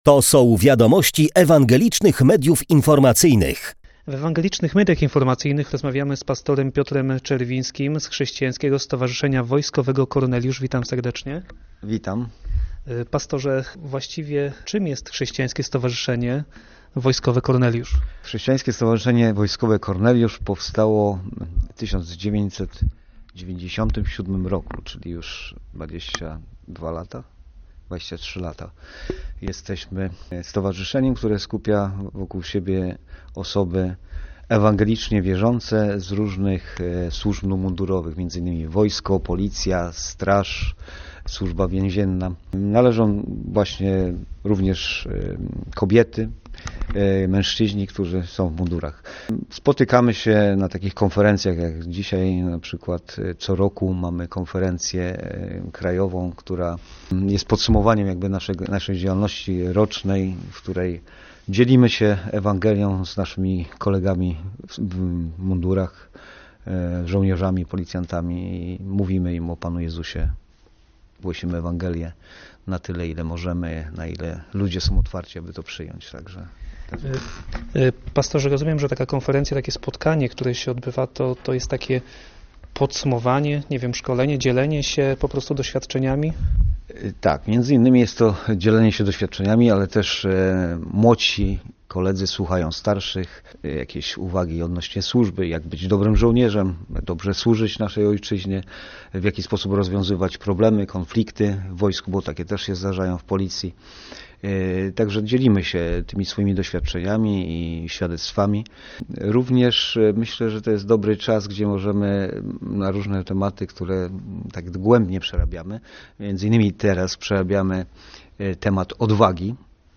Korneliusz-cala-rozmowa.mp3